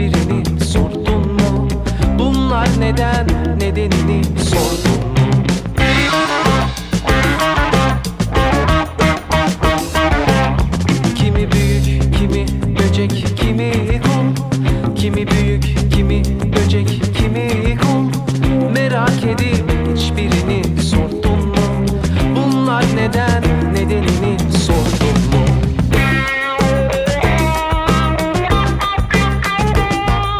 est un disque électrique aux accents funk et psychédélique